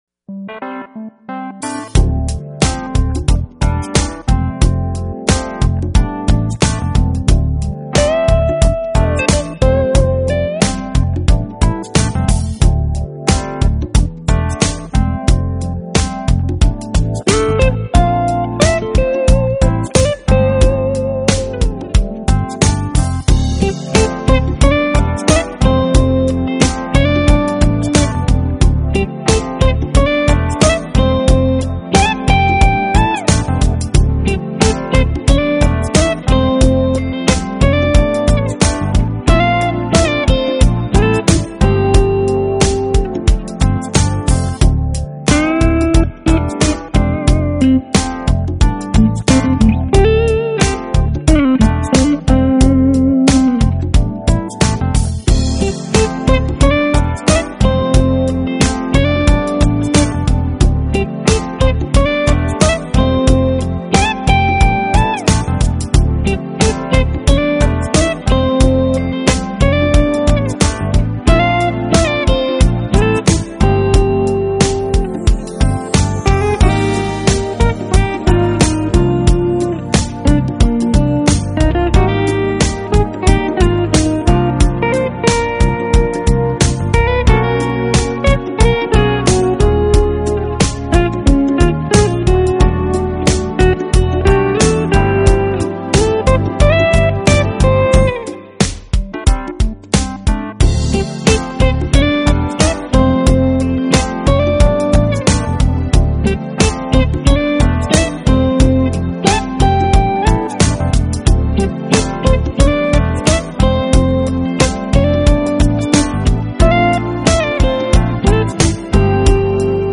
【爵士吉他】
流畅的爵